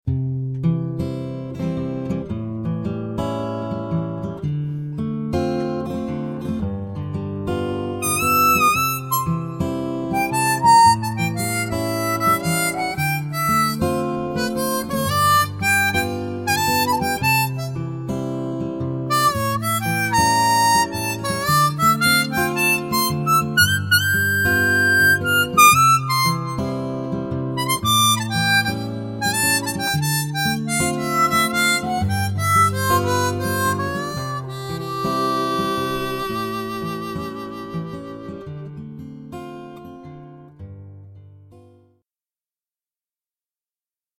overblow1.mp3